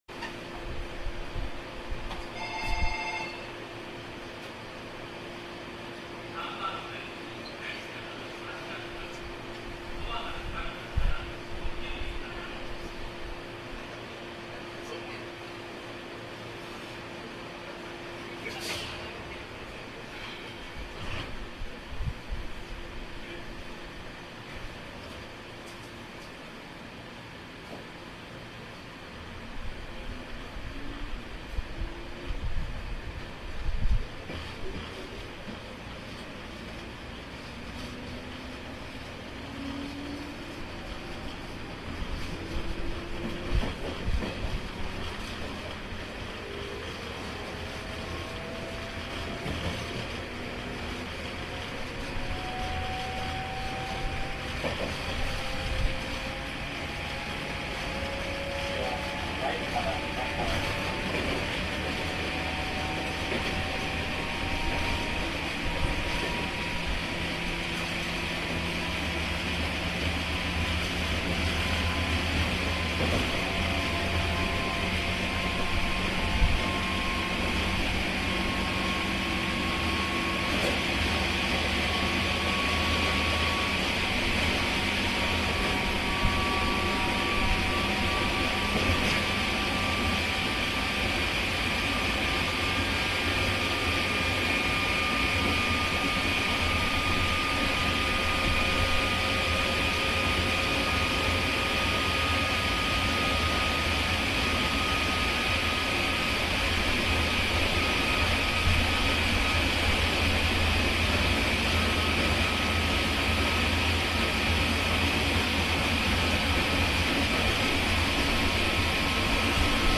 続いては高音モーターです。
個人的には高音のほうがより苦しそうに聞こえますね。